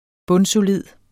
Udtale [ ˈbɔnsoˈliðˀ ]